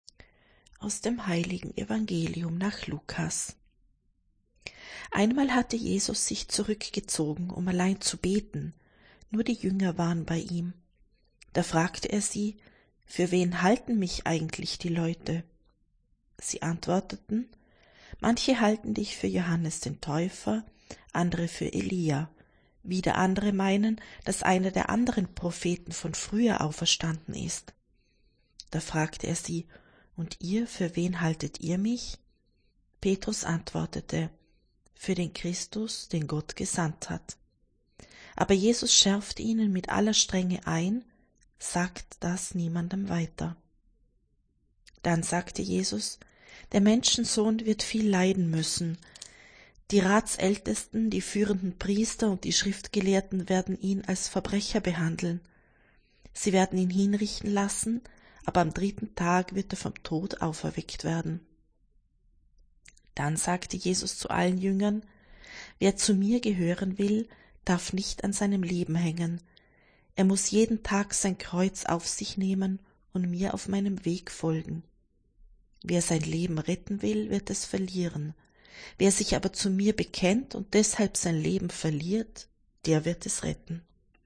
C-12.-So-i-jk-Evangelium-22.6.mp3